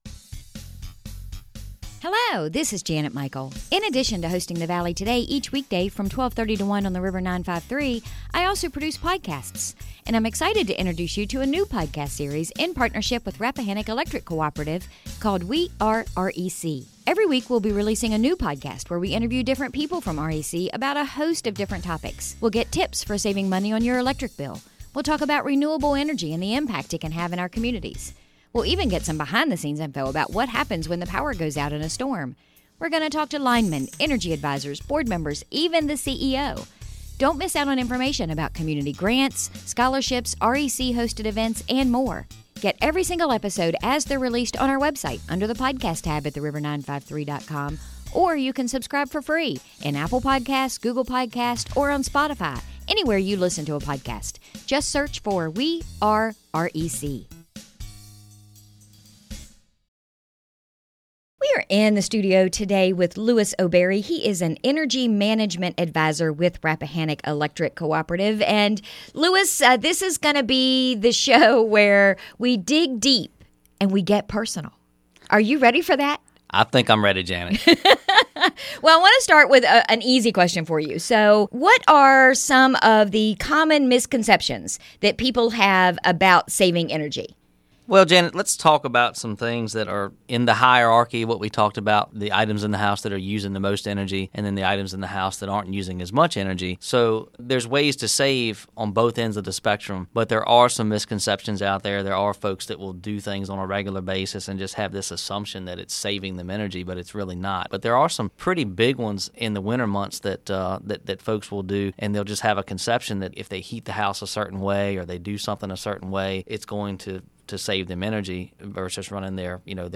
Our conversation today